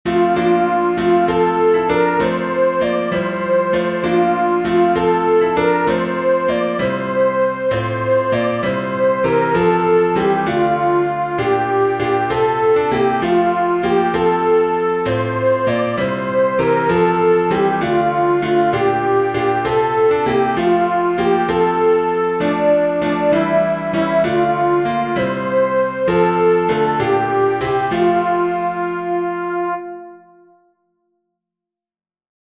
good_christian_friends-soprano1.mp3